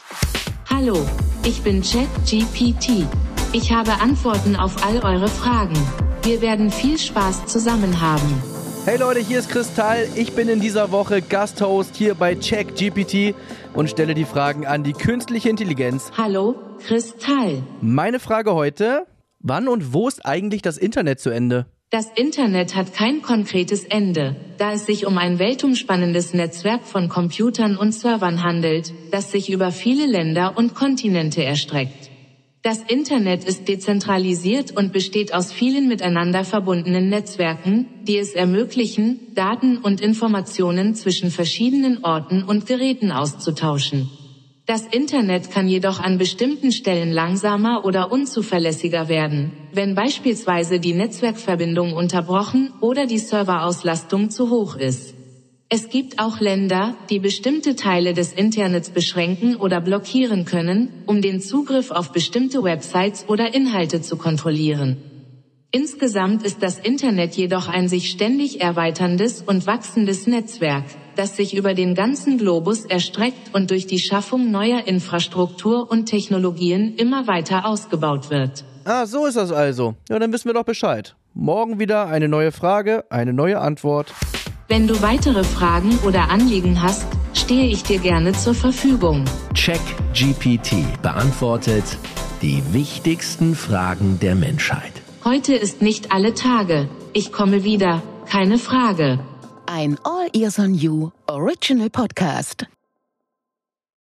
Chris Tall & KI